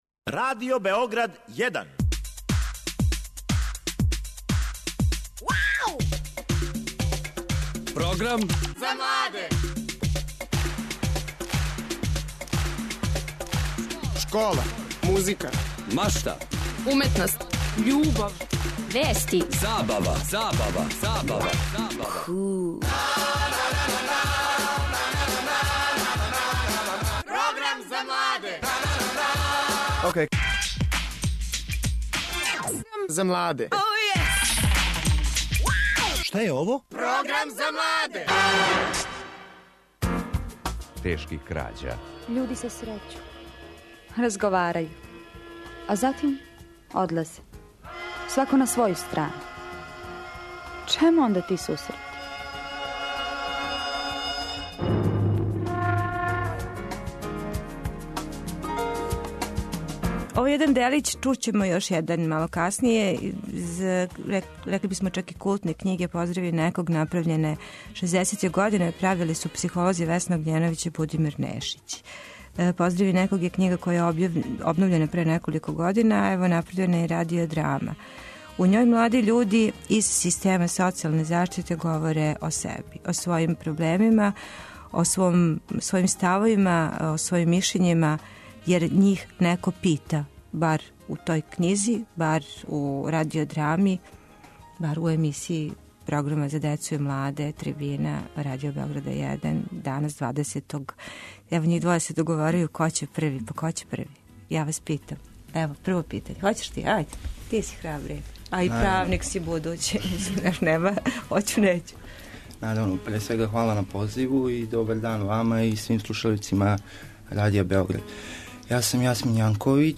О томе у Трибини говоре учесници Вршњачког саветовалишта Центра за социјално-превентивне активности ГРиГ.